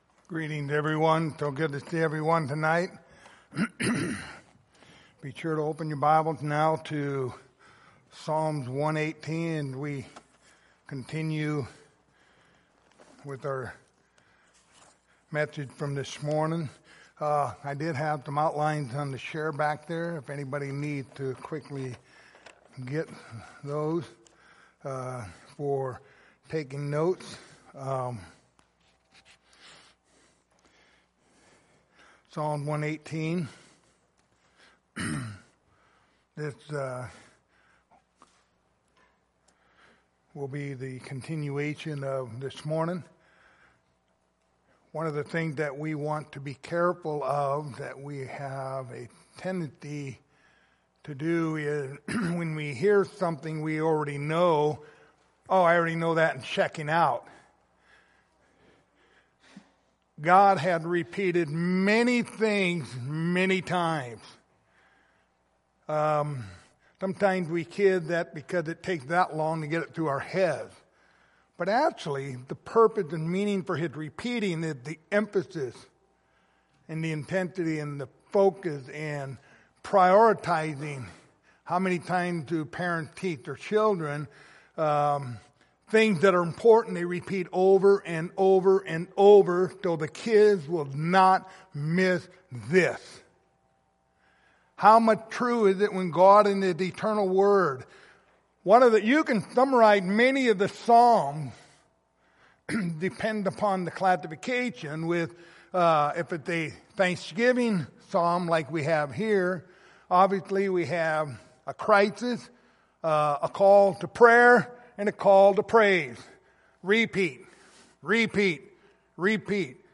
Passage: Psalms 118:19-29 Service Type: Sunday Evening